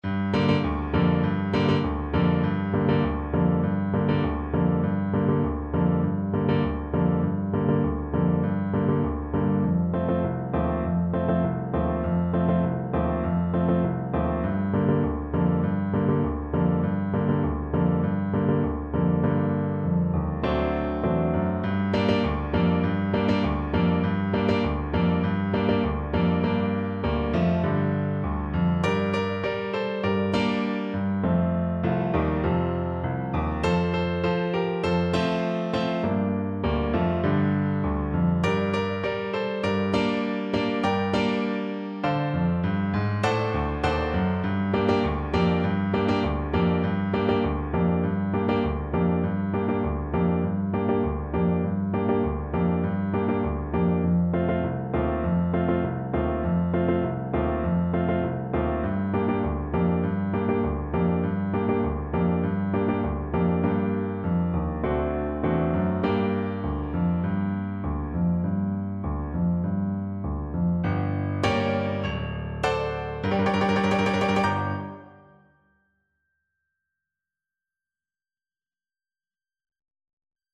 Clarinet
2/2 (View more 2/2 Music)
Fast two in a bar (=c.100)
Traditional (View more Traditional Clarinet Music)
sinner_man_CL_kar1.mp3